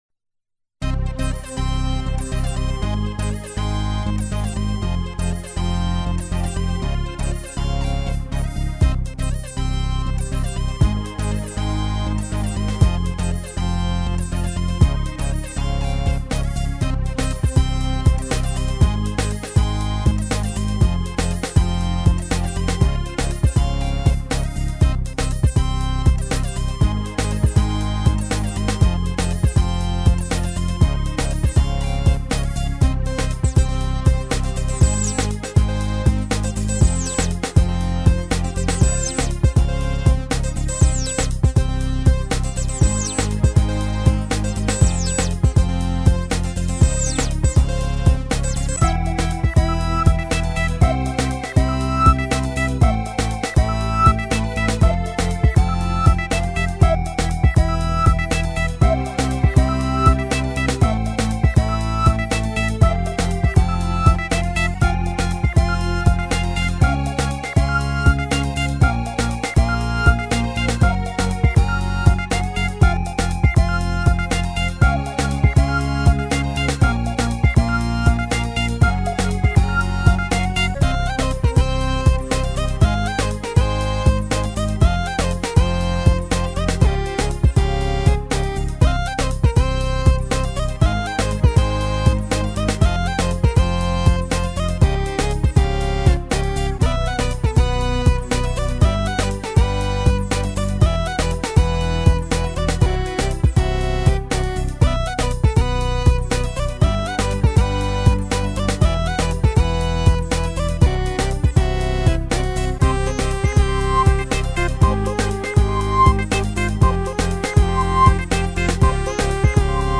Don't mind the suboptimal sound quality in some of the songs, most of them are intentionally not polished.
Very quicly made JX305 tune that sounds a bit like some old tracker-modules. I just made few different patterns and mix/transpose them to output a full song.